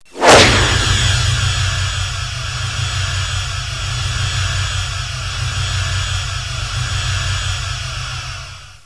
tractor.wav